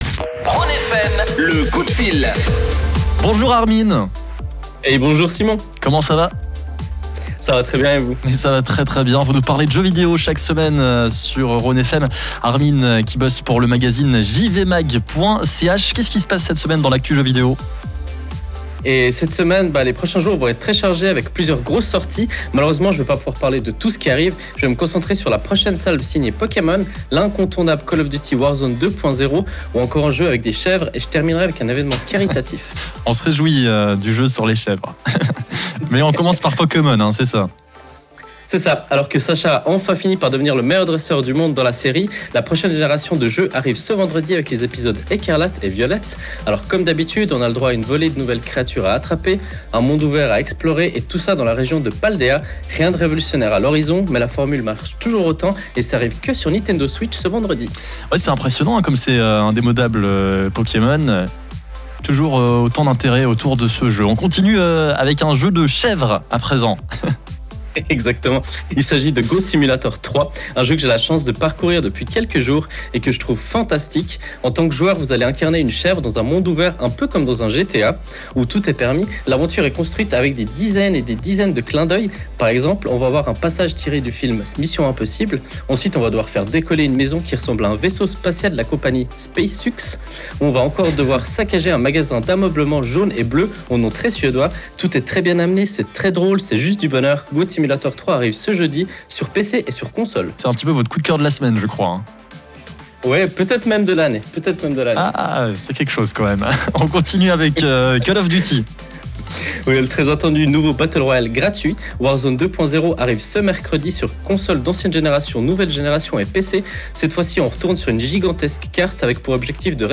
Pour réécouter le direct, ça se passe via le player en dessus.